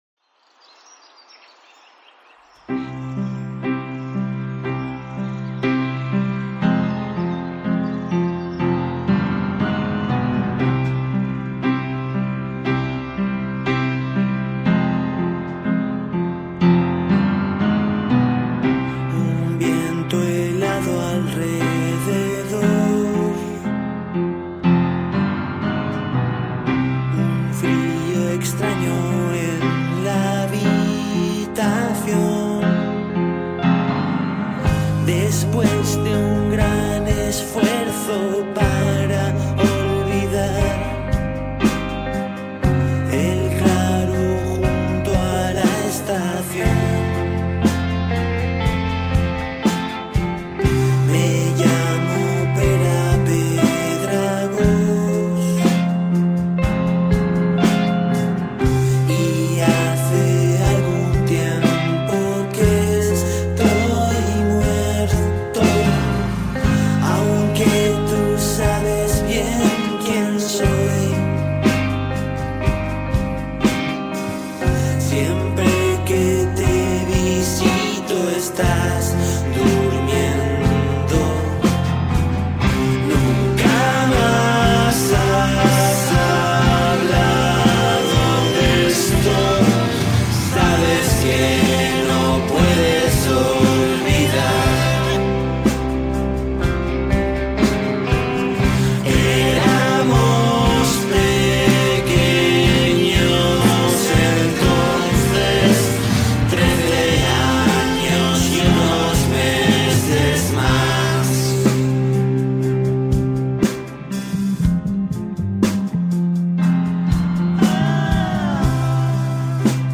Indie from Spain – with mellotrons, no less.